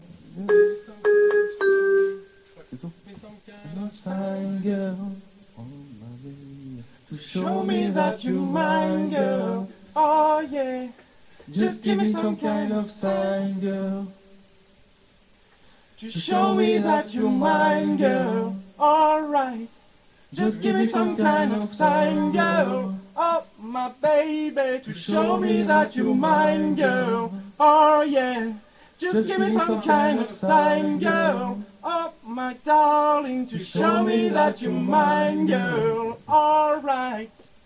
Nos choeurs... pour travail
choeurs_gimme.mp3